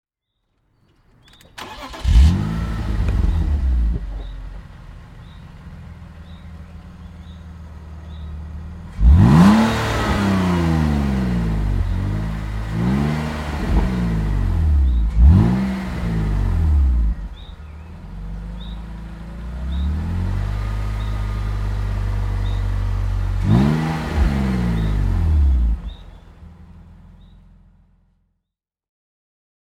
BMW Z3 2.8 Coupé (2000) - Starten und Leerlauf